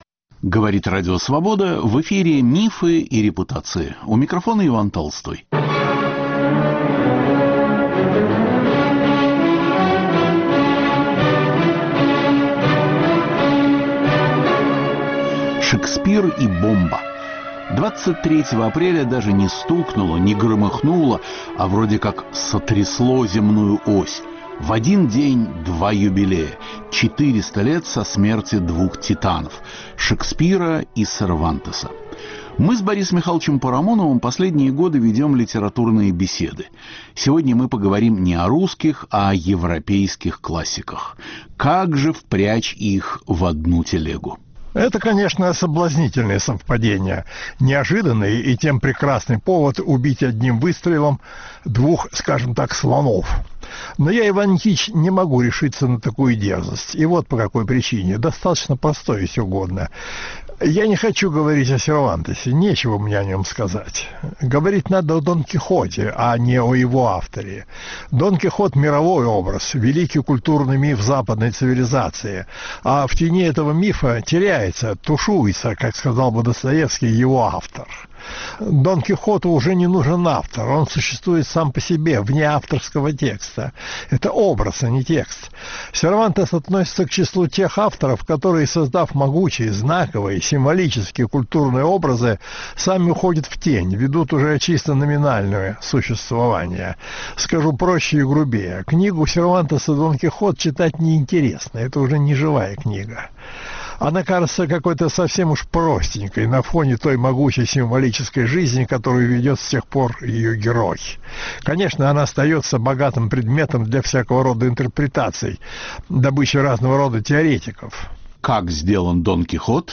Два великих юбилея – 400 лет со дня смерти Шекспира и Сервантеса. Диалог Бориса Парамонова с Иваном Толстым.